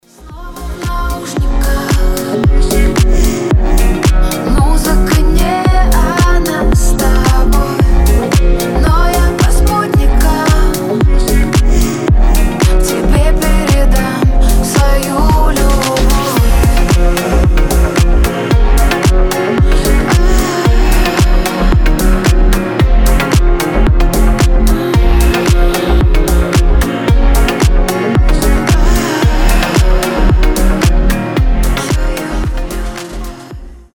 женский голос
чувственные
космические